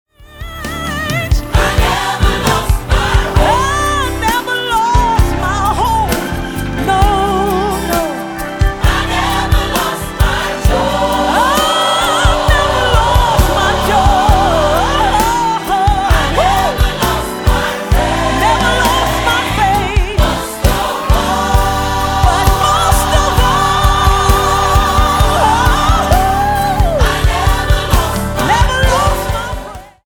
Slow
Key G Tempo 63.00